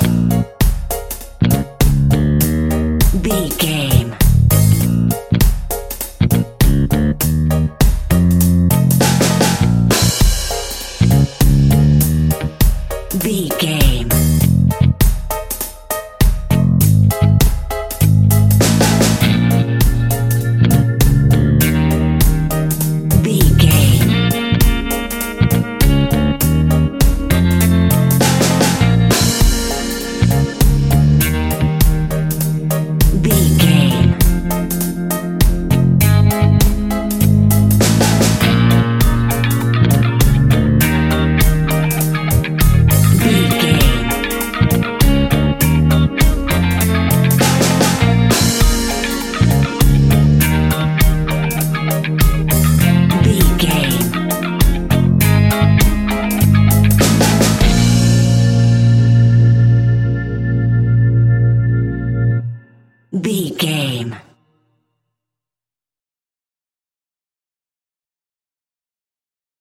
Aeolian/Minor
B♭
laid back
chilled
off beat
drums
skank guitar
hammond organ
transistor guitar
percussion
horns